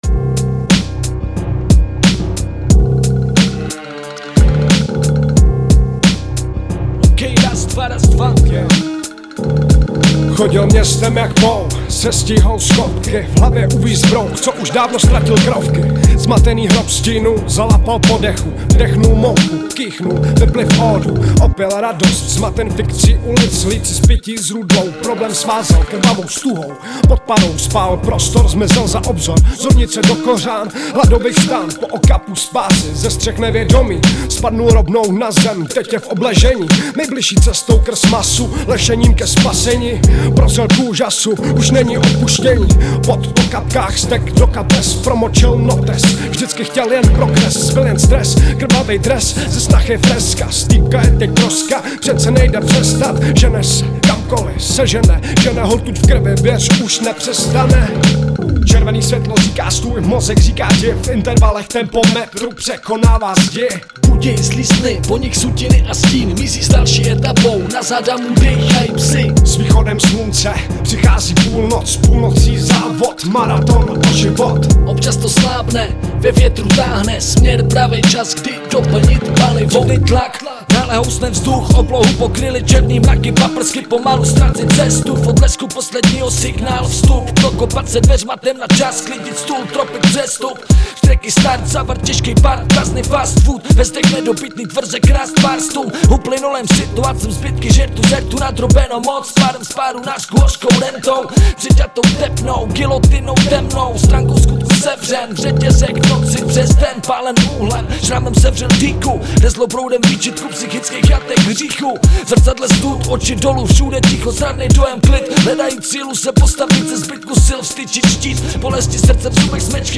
6 Styl: Hip-Hop Rok